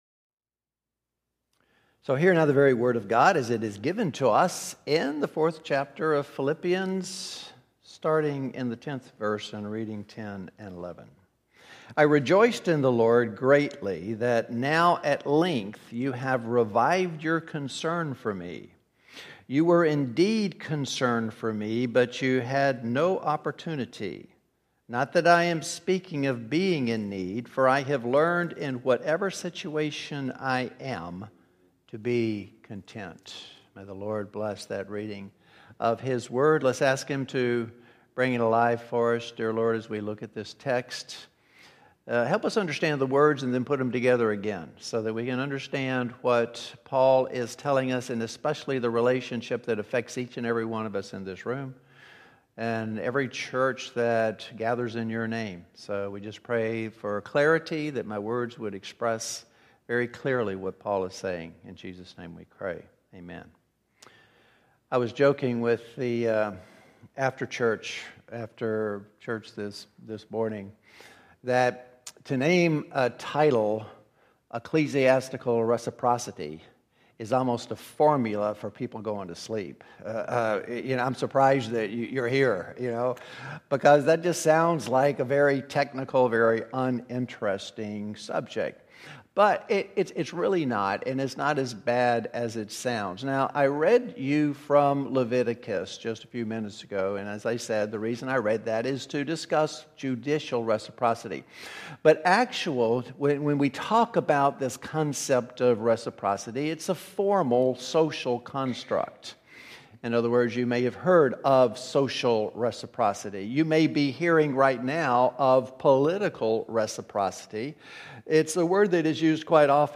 New Hope Sermons